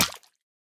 Minecraft Version Minecraft Version snapshot Latest Release | Latest Snapshot snapshot / assets / minecraft / sounds / entity / fish / hurt1.ogg Compare With Compare With Latest Release | Latest Snapshot
hurt1.ogg